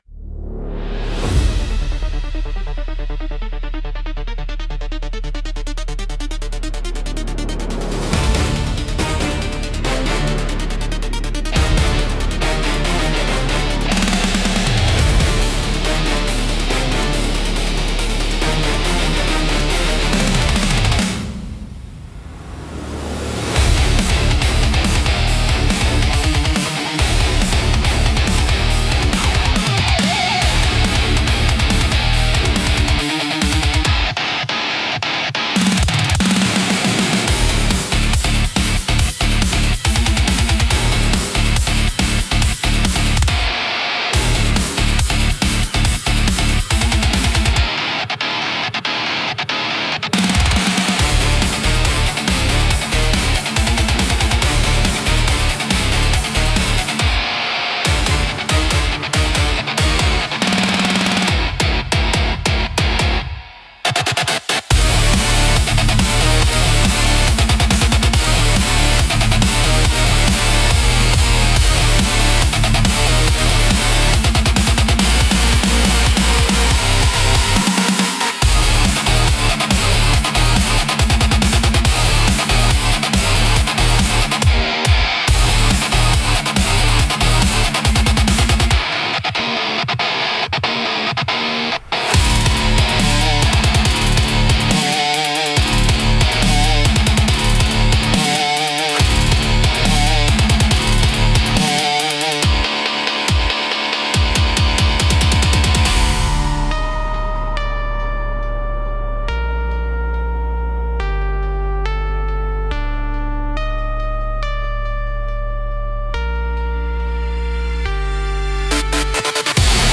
激しいリズムと力強い音色で、緊張感や切迫した状況を鋭く表現します。
オリジナルの緊迫は、迫力あるブラスセクションを中心に緊迫感を強調した曲調が特徴です。